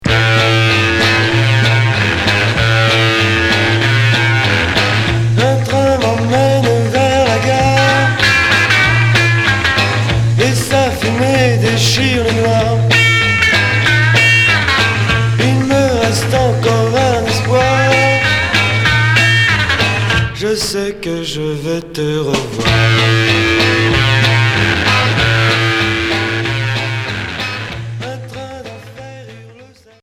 Chanteur 60's Unique EP